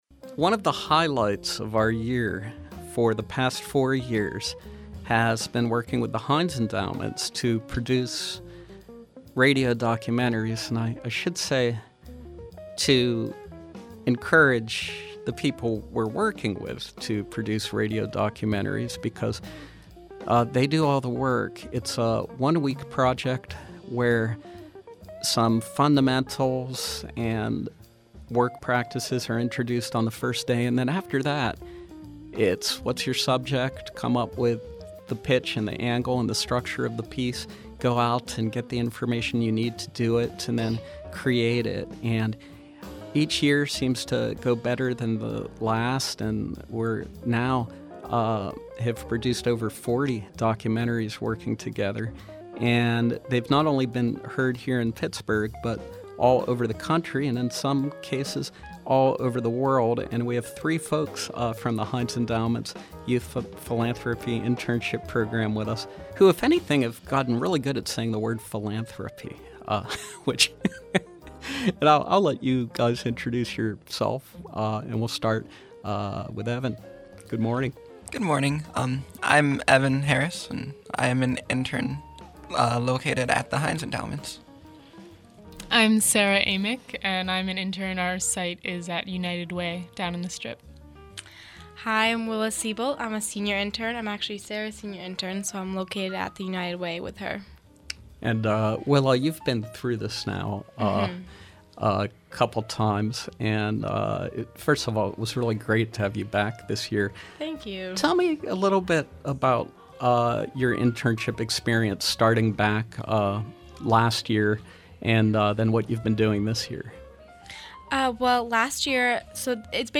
Rising college freshmen from The Heinz Endowments Youth Philanthropy Summer Internship Program discuss the nine radio documentaries they produced in July at the SLB studios.